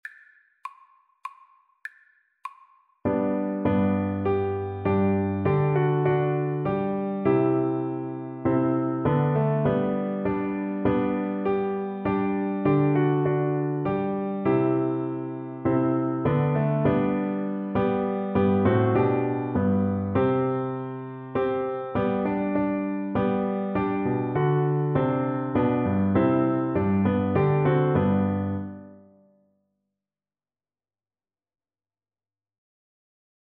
Christian
3/4 (View more 3/4 Music)
Classical (View more Classical Viola Music)